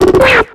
Cri de Kraknoix dans Pokémon X et Y.